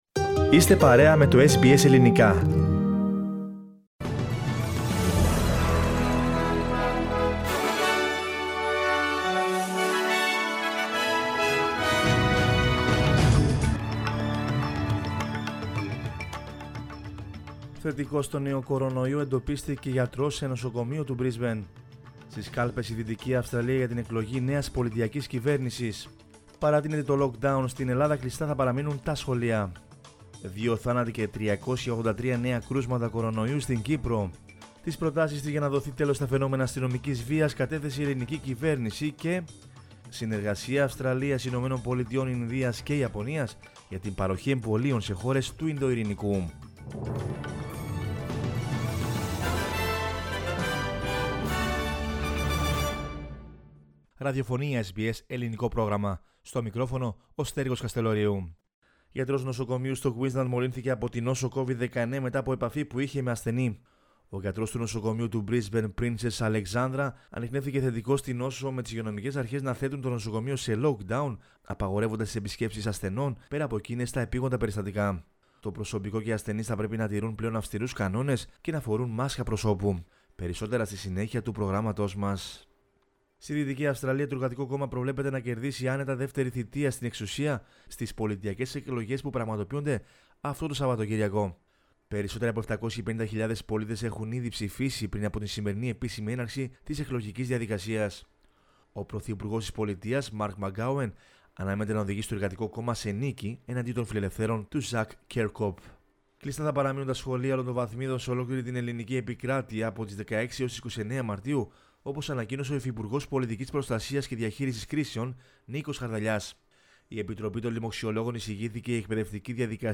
News in Greek from Australia, Greece, Cyprus and the world is the news bulletin of Saturday 13 March 2021.